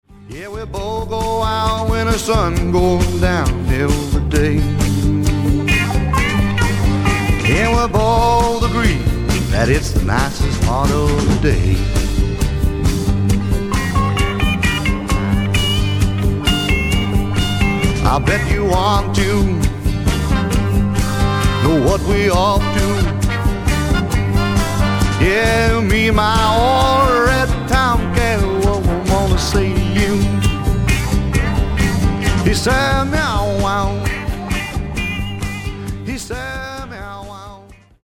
ヴォーカル、12弦ギター
ベース
パーカッション
ピアノ